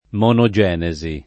[ m q no J$ ne @ i ]